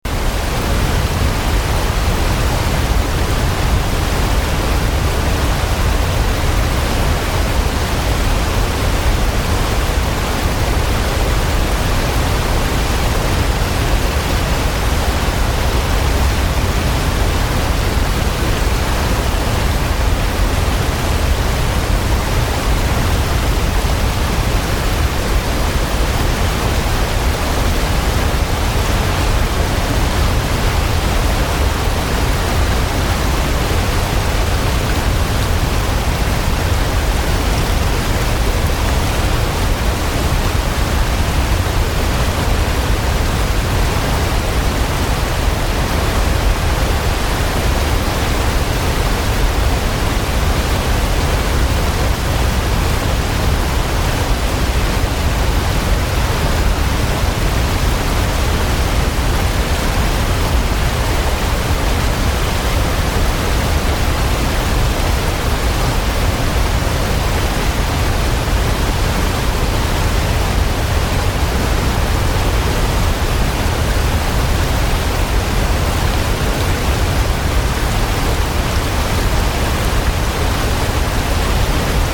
Download Waterfall sound effect for free.
Waterfall